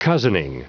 Prononciation du mot cozening en anglais (fichier audio)
Prononciation du mot : cozening